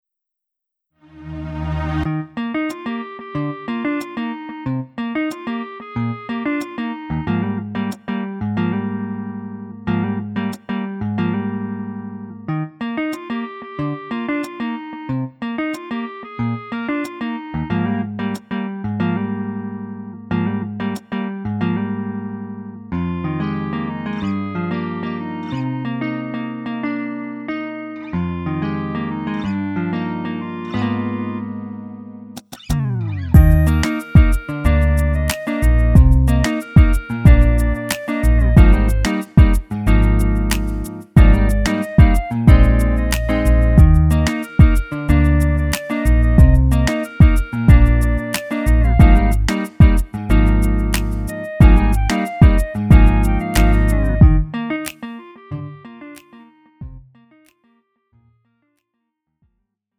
음정 -1키 2:42
장르 가요 구분 Lite MR
Lite MR은 저렴한 가격에 간단한 연습이나 취미용으로 활용할 수 있는 가벼운 반주입니다.